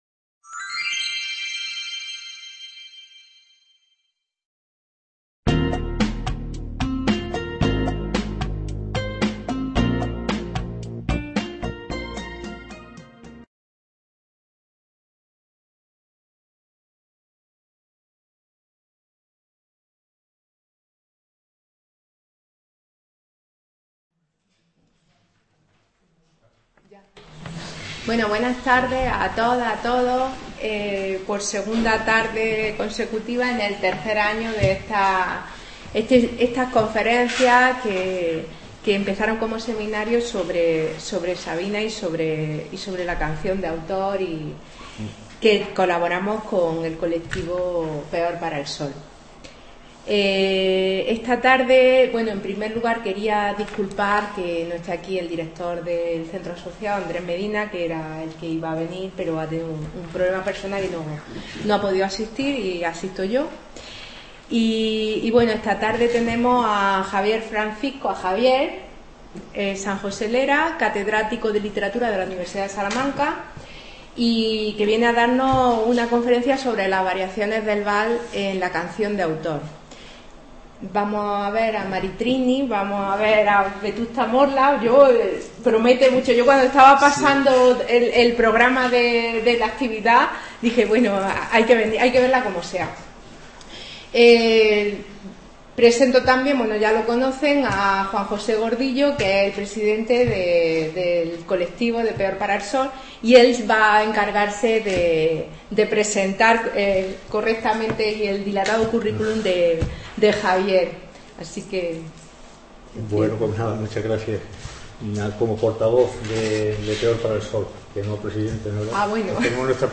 conferencia